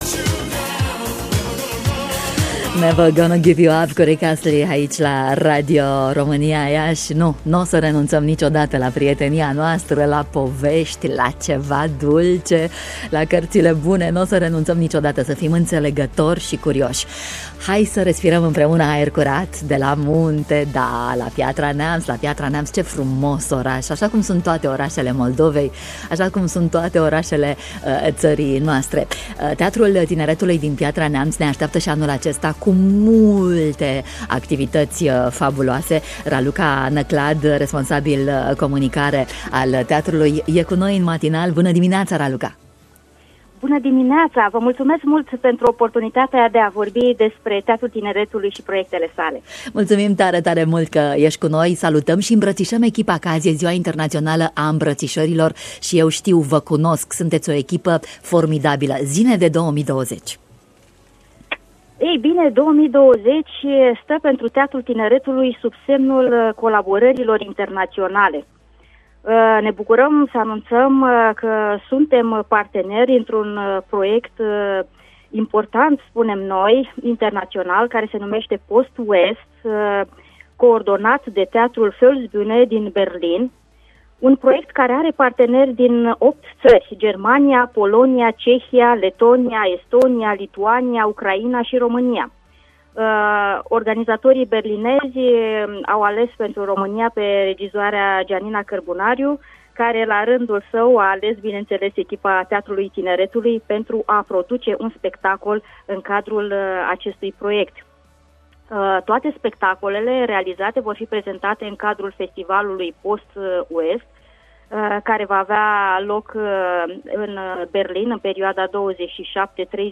în matinalul Radio România Iaşi: